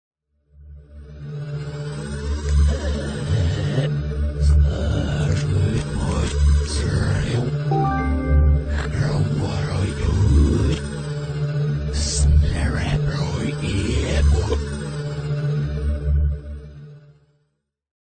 Genere: techno
Rovesciato
Incomprensibile